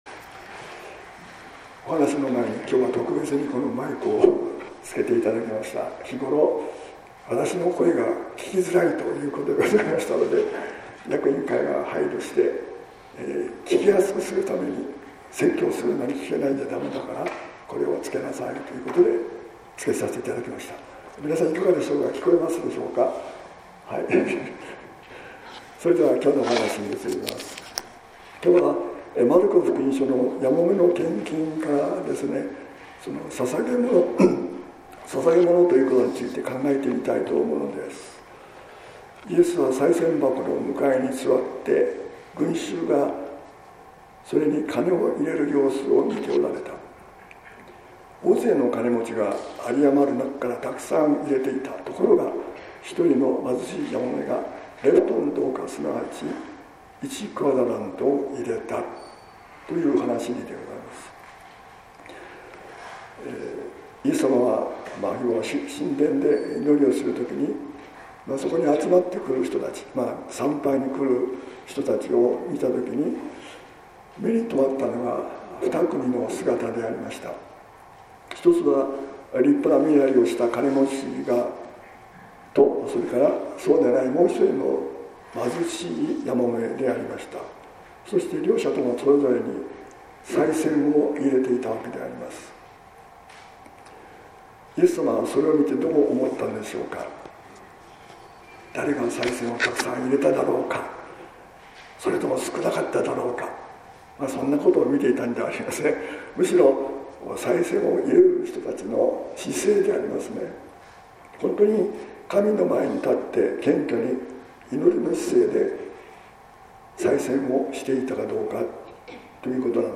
説教「心の豊かさ、貧しさ」（音声版）